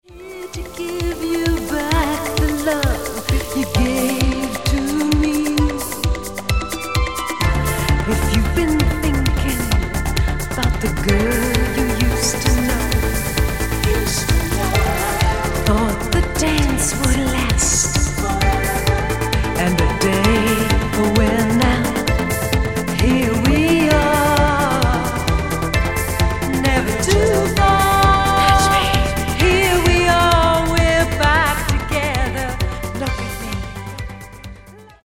Genere: Disco | High Energy